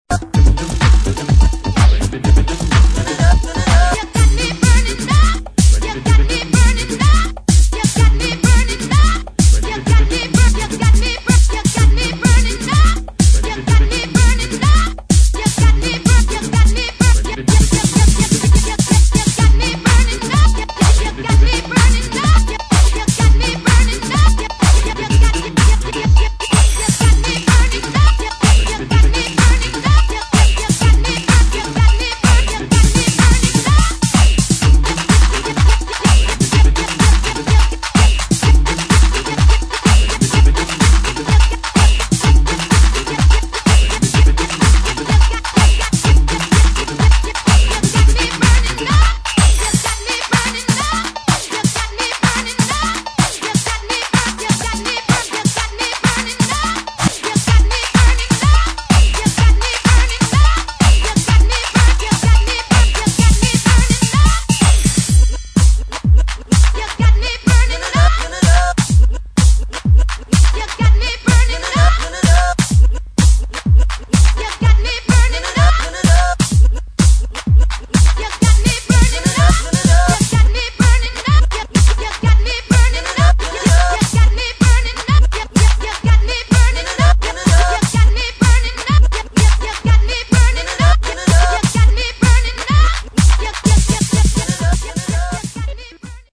[ HOUSE / DISCO ]